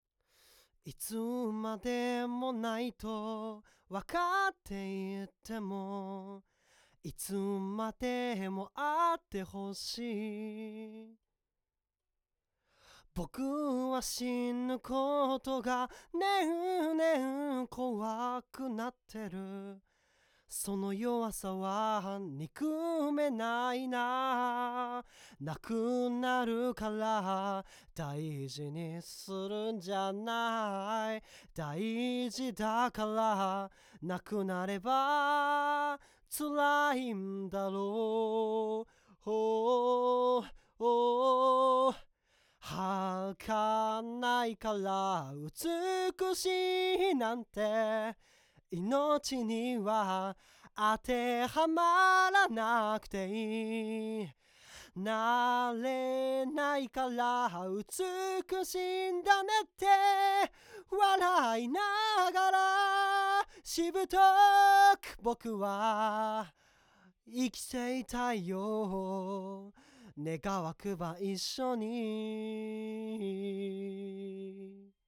そこで今回は、サウンドスタジオノア都立大店の10階にあるRECスタジオで2種類のコンデンサーマイクを使用したボーカルレコーディングを行いました。
ポップガードを拳1つ分開けて設置し、ボーカルの鼻腔共鳴が強いということもあり、口元を少し斜めに狙うようにしました。
また、部屋鳴りの確認のためリフレクションフィルターは使用していません。
まず今回使用したRECスタジオの部屋鳴りについて、音は少しウェットよりになり、ナレーションやラジオ撮りに向いている印象でした。
Austrian Audio oc18
それに比べ、oc18の方が暖かみのある音になり、低域よりな音が個人的に気に入りました。